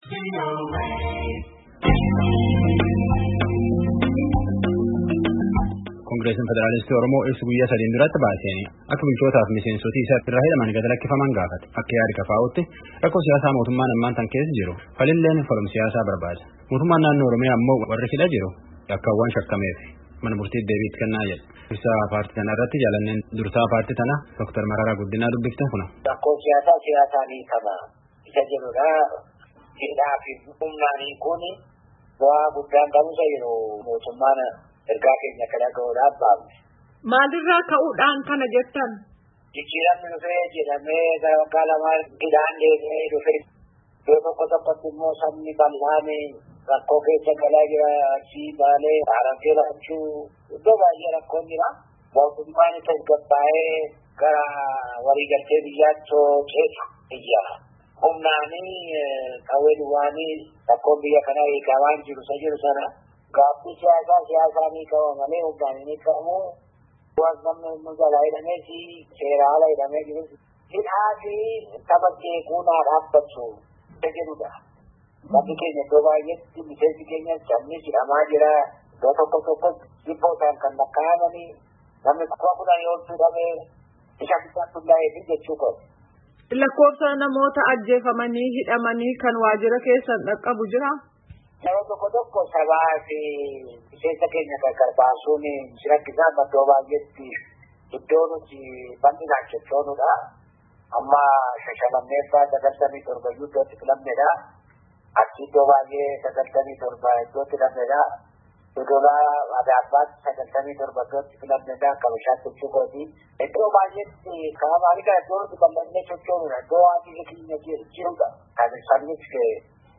Dura ta'aa Kongiresii Federaalawaa Oromoo, Dr. Mararaa Guddinaa fi Hogganaa Biroo Koominikeeshinii mootummaa naannoo Oromiyaa, Obboo Getaachoo Baalchaa
Gaaffii fi Deebii Gaggeeffame Caqasaa.